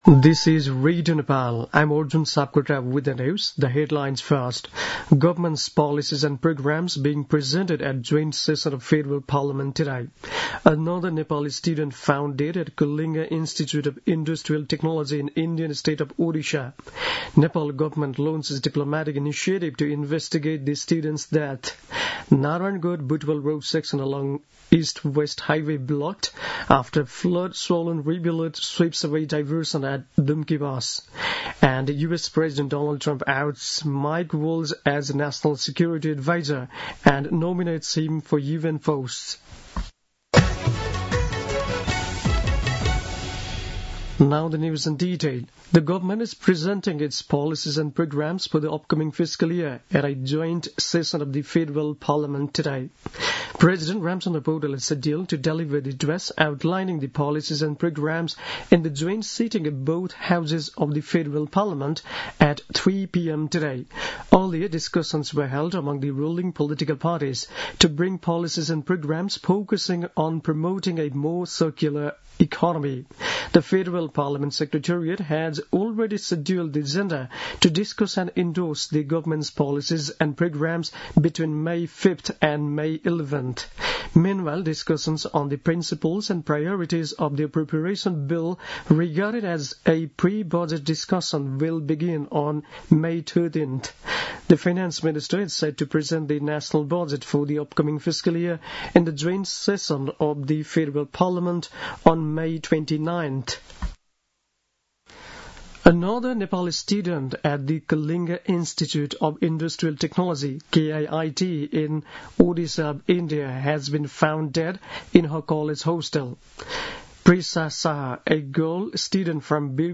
दिउँसो २ बजेको अङ्ग्रेजी समाचार : १९ वैशाख , २०८२